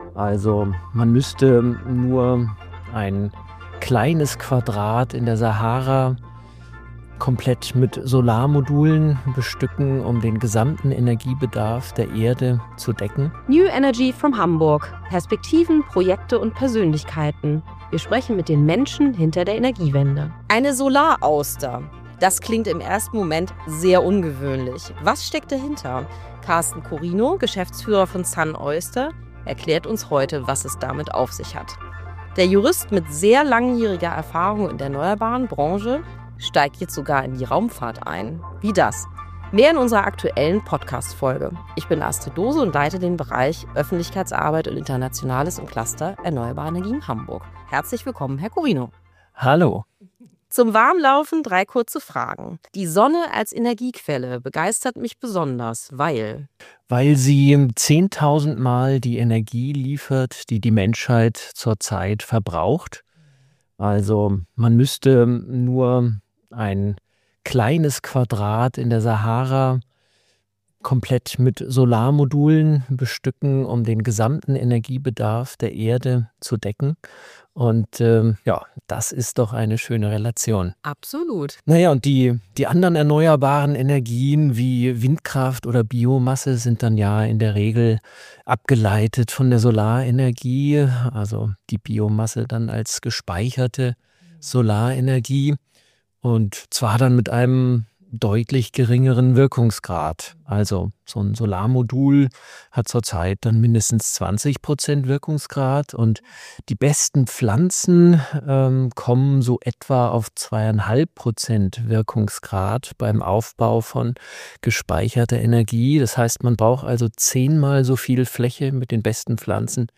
Gerade auf Dächern mit wenig Traglast eröffnet das neue Möglichkeiten - etwa auf Industriehallen, Tankstellen oder sogar in Produktionshallen für die Ariane 6. Ein Gespräch über neue Ideen in der Solarenergie und darüber, wie viel Potenzial auf leichten Dächern noch ungenutzt ist.